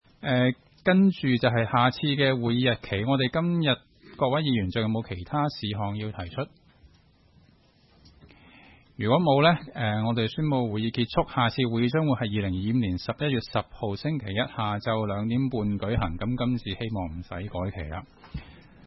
区议会大会的录音记录
屯门区议会会议室